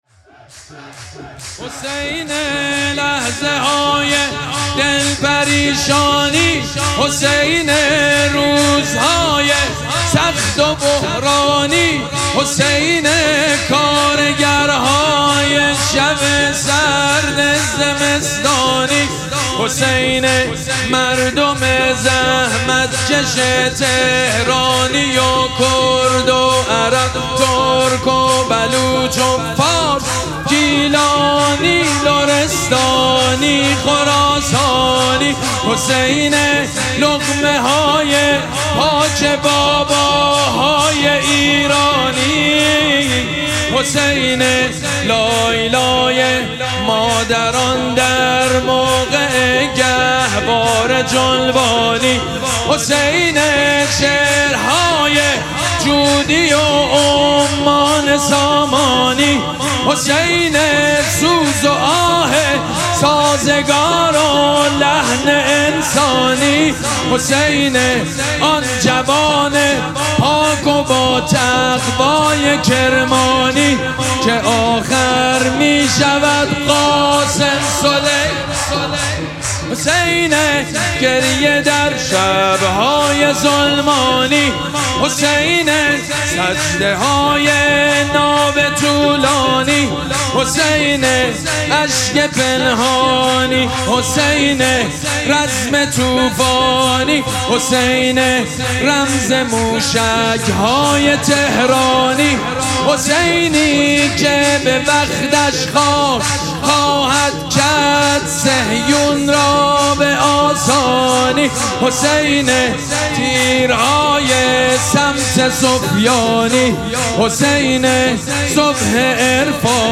شب دوم مراسم جشن ولادت سرداران کربلا
حسینیه ریحانه الحسین سلام الله علیها
سرود
مداح
حاج سید مجید بنی فاطمه